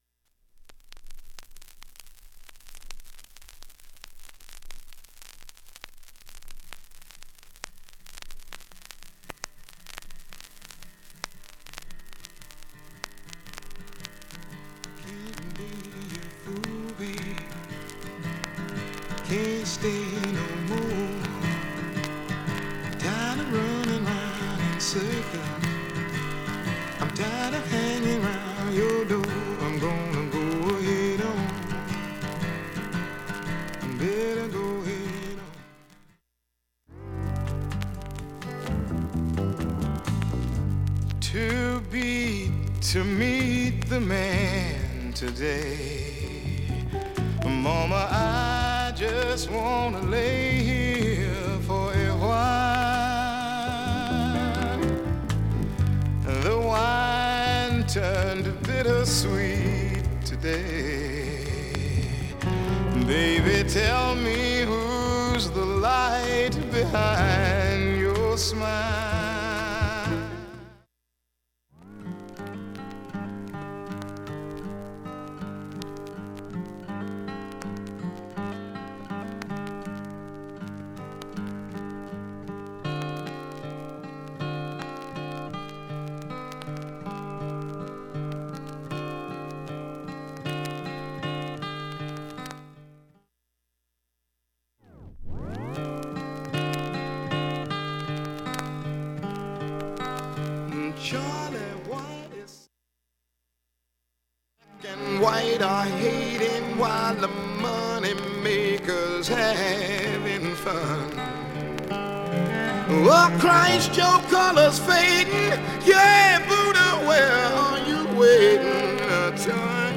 静かな部でもかすかなレベルです。
B-５序盤以降はかなりいい音質です
1,A面始めに１３回かすかなプツ
5,(4m14s〜)B面は少しチリプツが出ている
１５回までのかすかなプツが２箇所